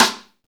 SNR XXSTI0PR.wav